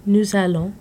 Click each word to hear the pronunciation.